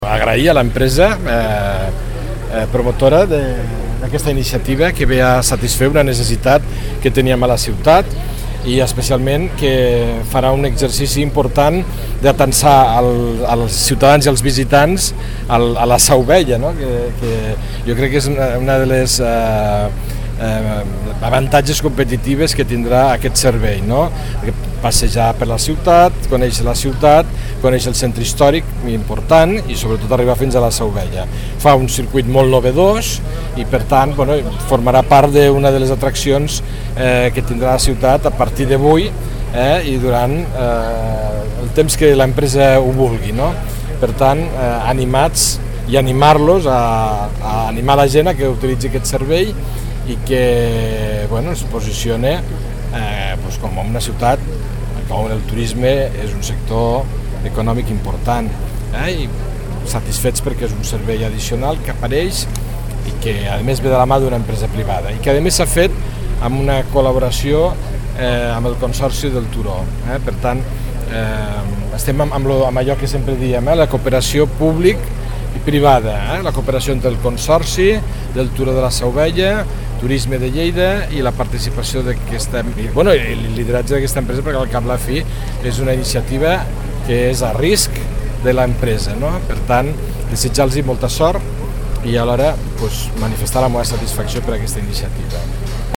tall-de-veu-del-tinent-dalcalde-felix-larrosa-sobre-la-posada-en-marxa-del-tren-turistic-a-lleida